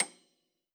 53r-pno30-C7.wav